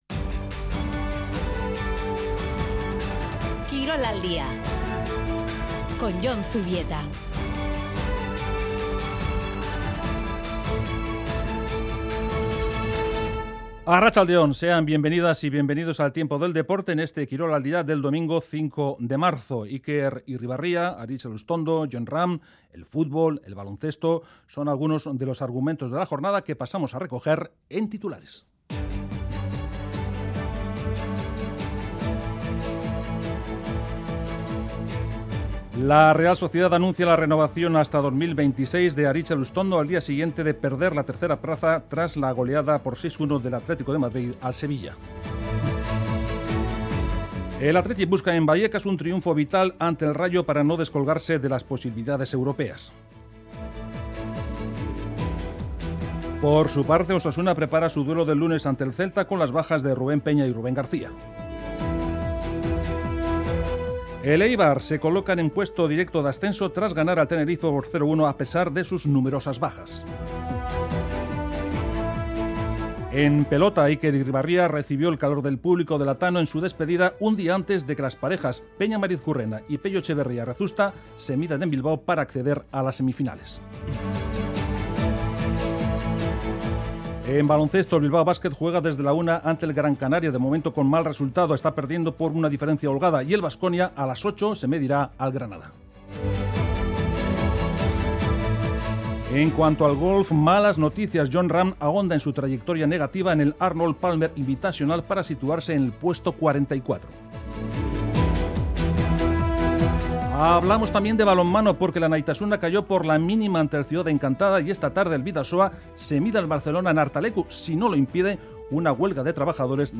Informativo de actualidad deportiva